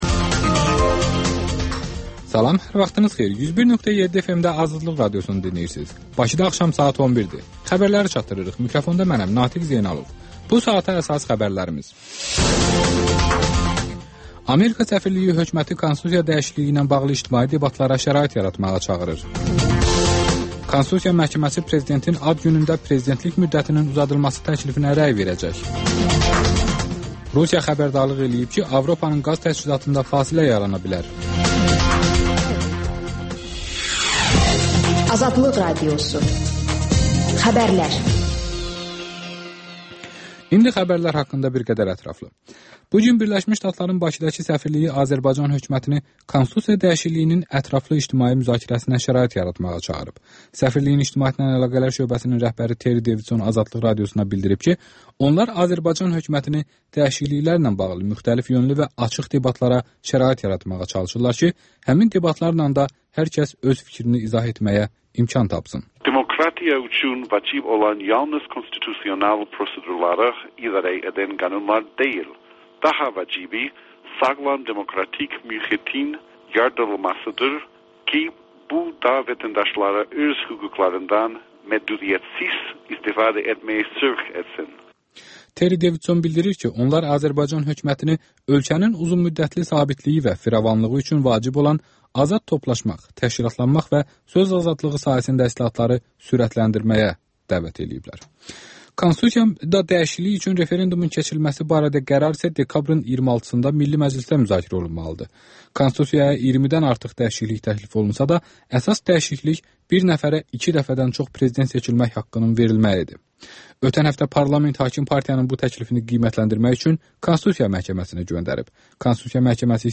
Xəbərlər, Kontekst və XÜSUSİ REPORTAJ